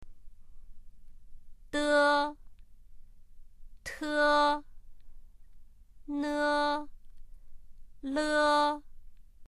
（　）の母音をつけた第一声の発音を聞いてみましょう。
d　（e）　　 t　（e）　　 n　（e）　 　l　（e）
de-te-ne-le.mp3